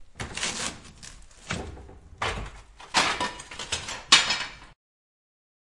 废弃的工厂金属后世界末日的回声" 废弃的金属
描述：记录在爱尔兰都柏林的废弃工厂。使用Zoom H6和Rode NT4。
Tag: 空间 噪音 金属 工业 回声